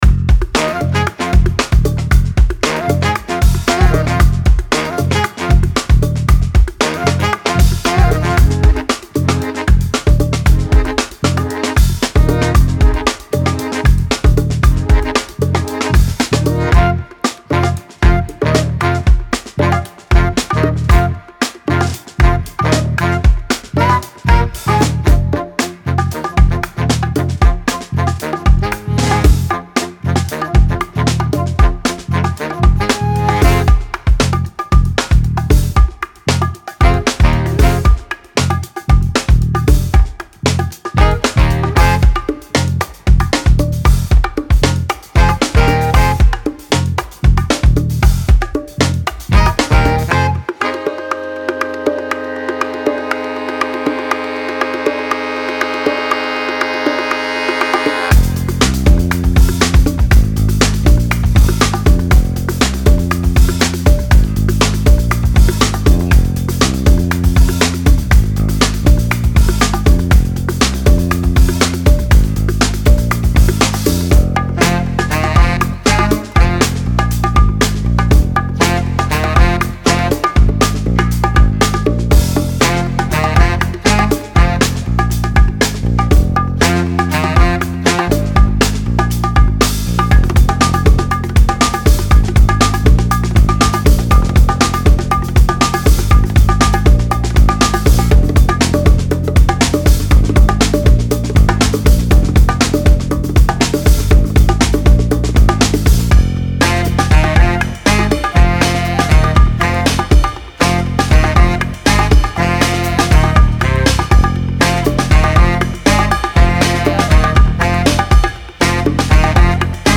手鼓
由邦戈鼓、杰贝鼓等手击打乐器演奏出的鲜活而强劲的节奏。其特点是温暖且自然。
收录乐器：邦戈鼓、康加鼓、达布卡鼓、杰贝鼓、伊博鼓及非洲打击乐器。
Bongos_Demo.mp3